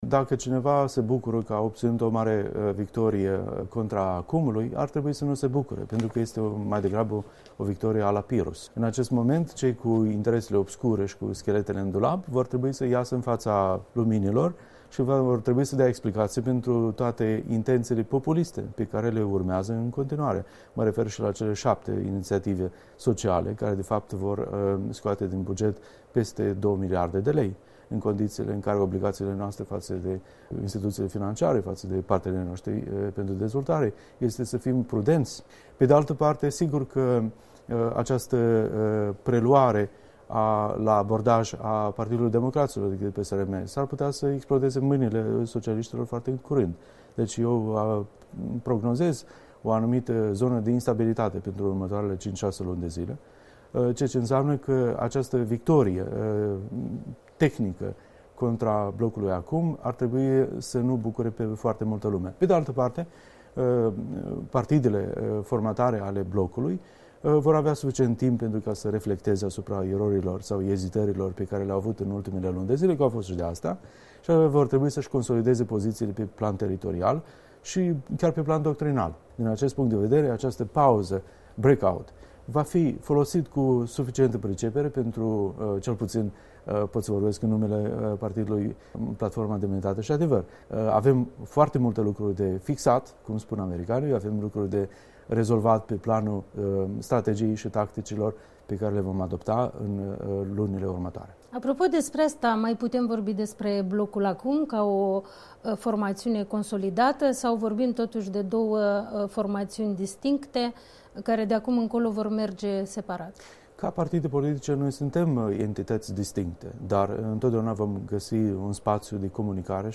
Interviu cu deputatul Blocului ACUM, ales recent vicepreședinte al Partidului Platforma „Demnitate şi Adevăr”.